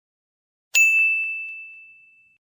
Bell_Ding_Sound_EFFECT(256k).mp3